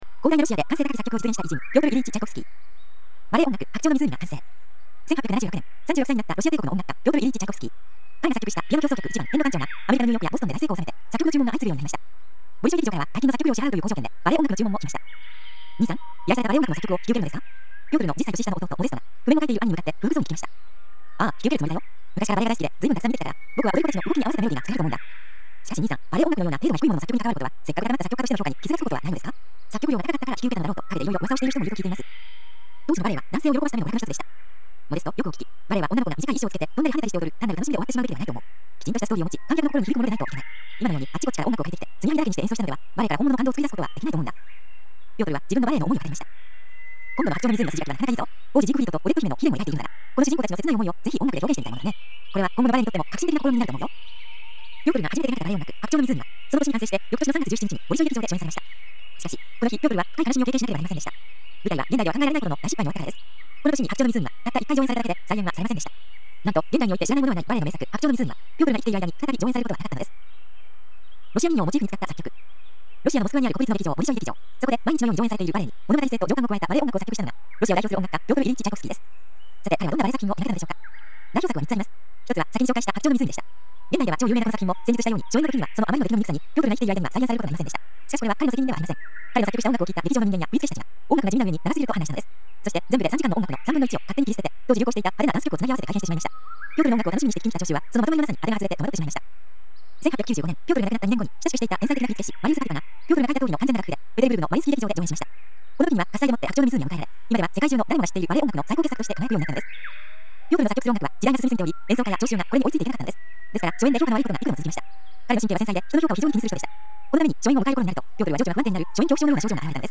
森の中の鳥のさえずりなどの自然音がバックに流れる中、独自のコンテンツや価値ある講演などの音声を、１倍速から無理なく段階的に高速再生し、日々音楽のように楽しく聴くことによって、年齢に関係なく潜在意識を “脳力全開”させていくシステムです
歴史上の偉人たちの話を、わかりやすい文章と穏やかな語りでお伝えする朗読ＣＤです